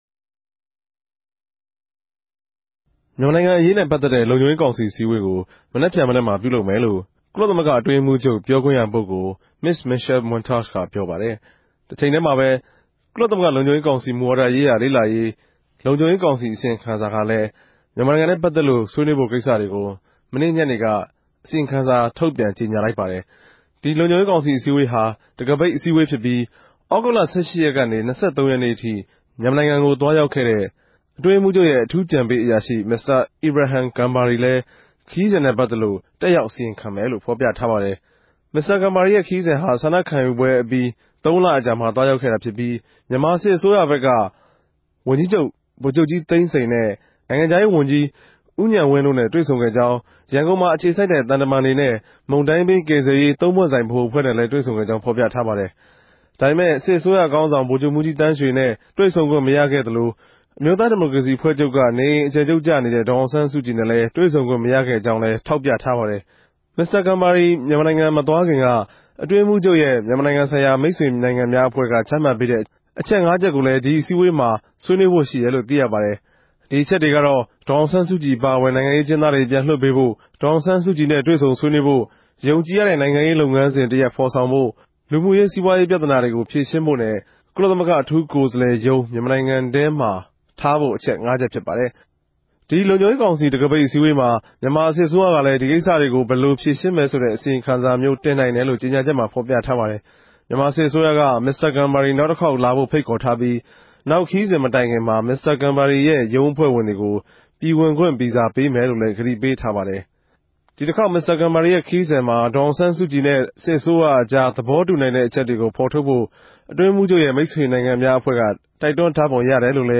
ကုလသမဂ္ဂဆိုင်ရာသတင်းပေးပိုႛခဵက်။